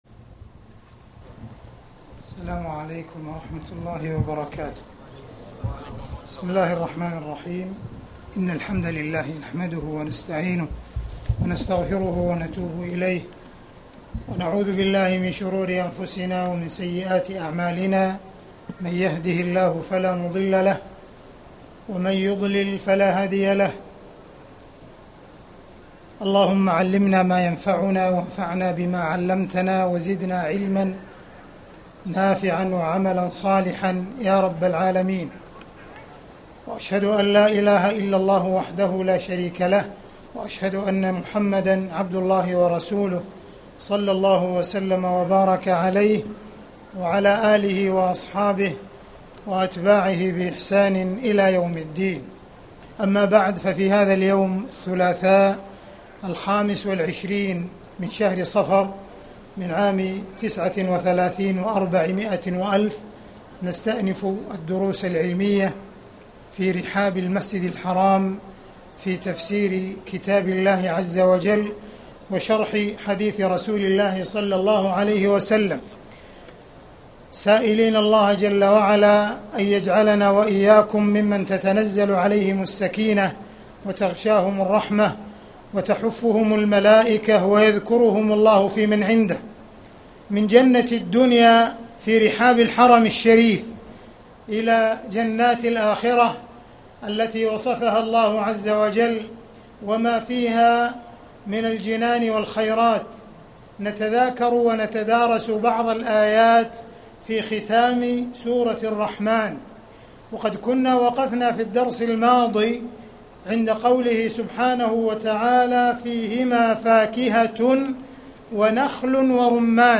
تاريخ النشر ٢٥ صفر ١٤٣٩ هـ المكان: المسجد الحرام الشيخ